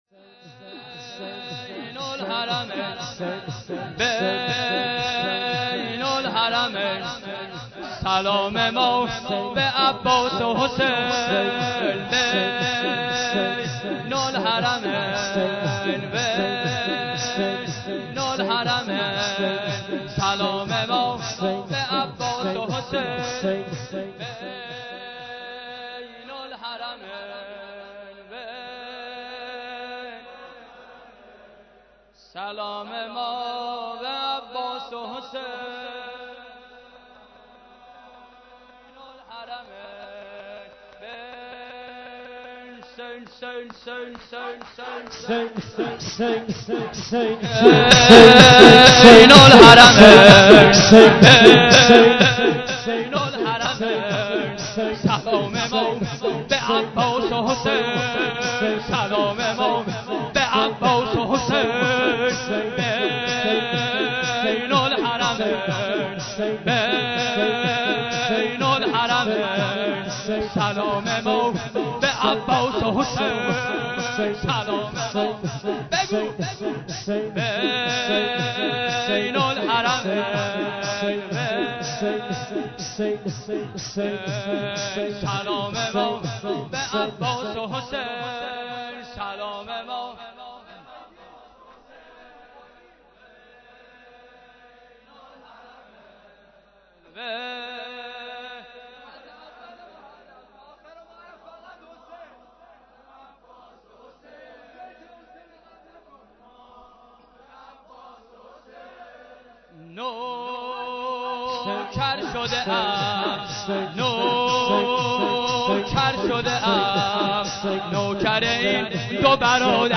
شور شب هفتم محرم 93
محرم93 - هیات انصارالمهدی بندرامام خمینی ره